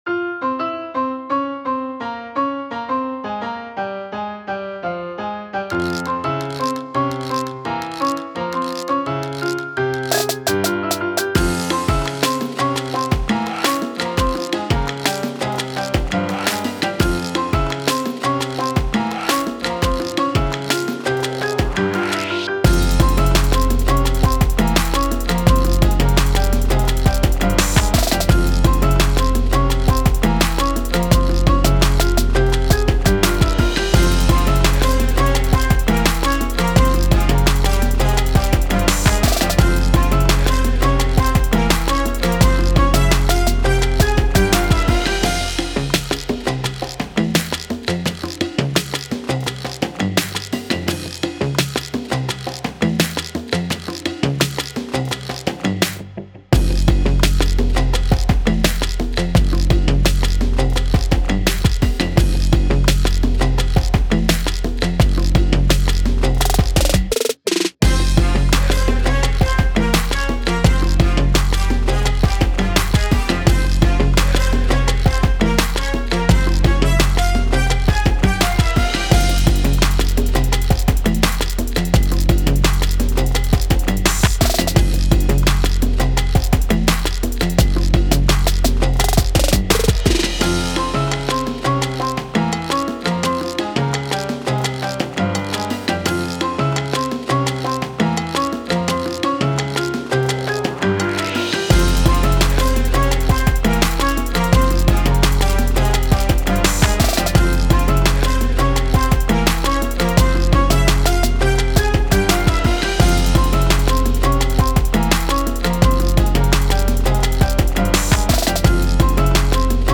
Emotion: Latin, Hip Hop, fun, party, Miami